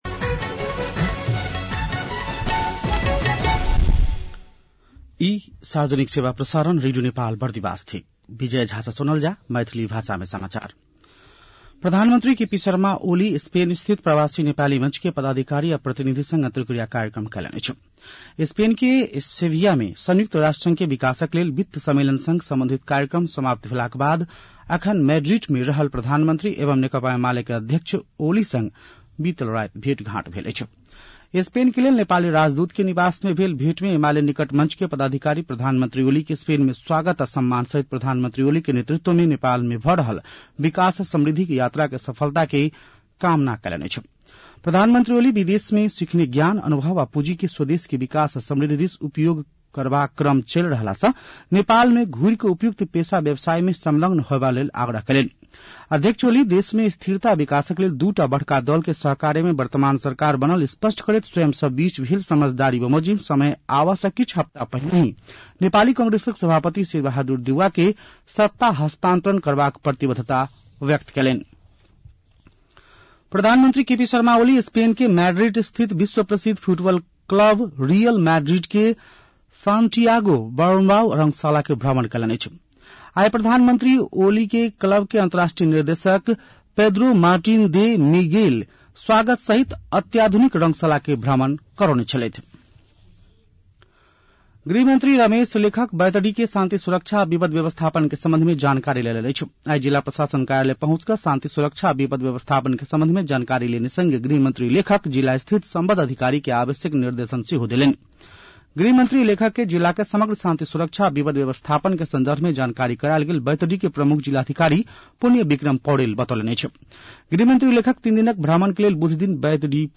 मैथिली भाषामा समाचार : १९ असार , २०८२